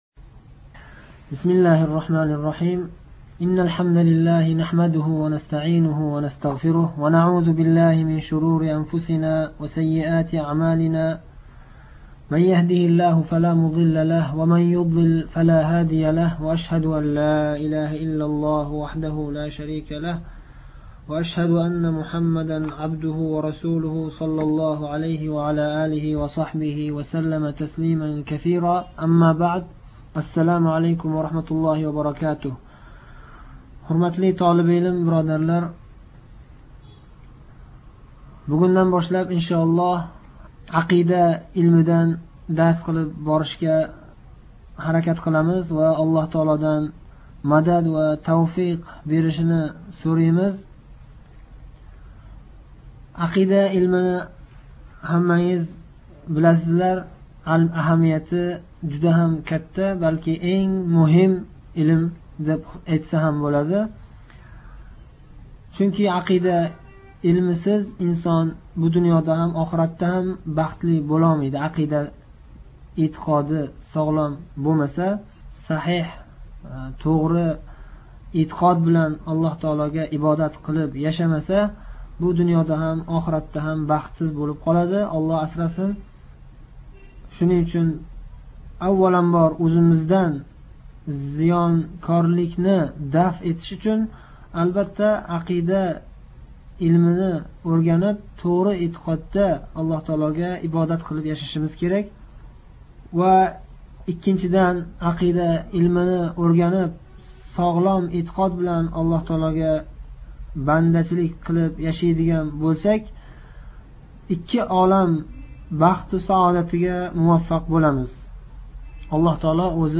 01_tahoviya.darsi.kirish.rm